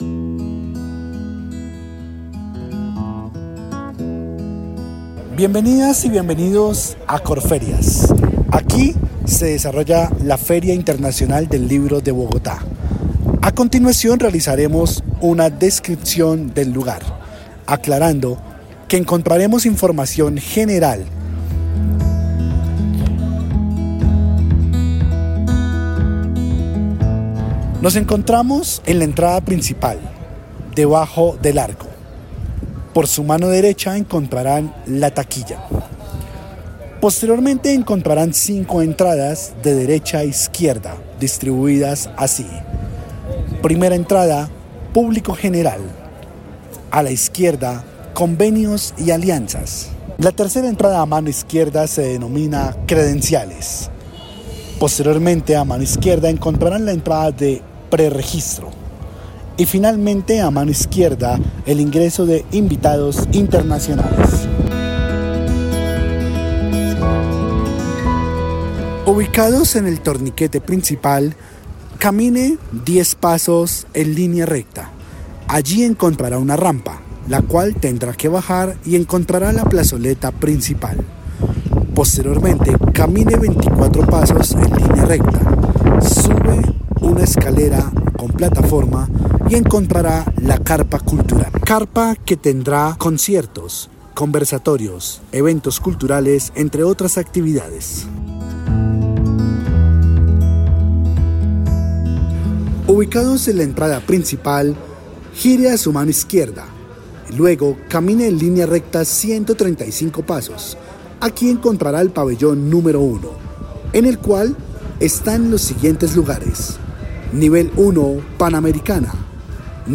Audio descripción Corferias FILBo 2024
AUDIODESCRIPCION-FILBO-2024.mp3